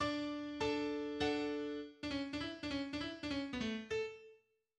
KeyD major
• Violin
1. Allegro maestoso